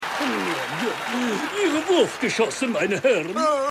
Synchronstudio: Iyuno Germany GmbH